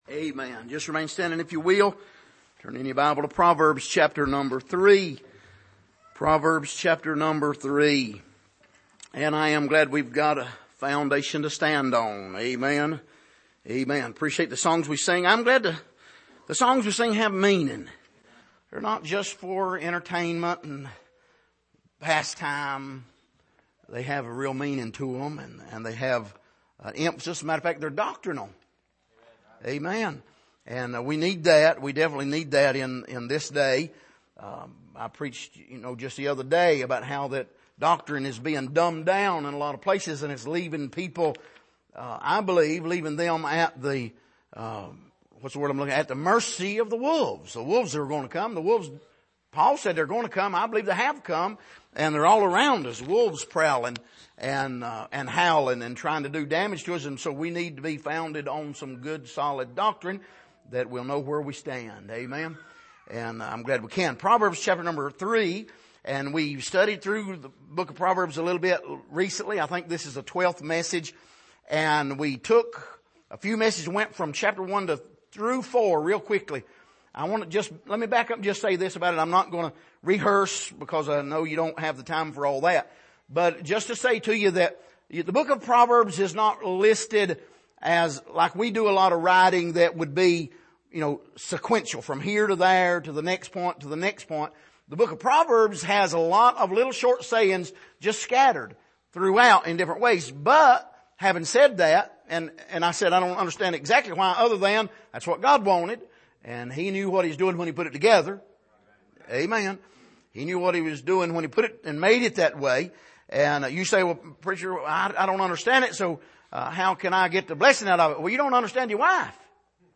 Passage: Proverbs 3:5-6 Service: Sunday Evening